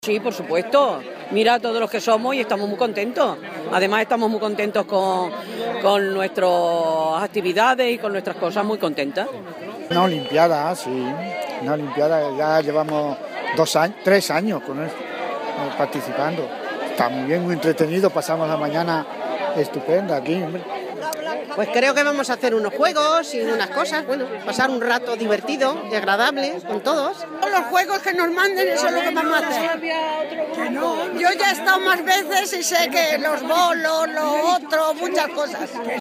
Audio de mayores en las IV Olimpiadas de Mayores de Móstoles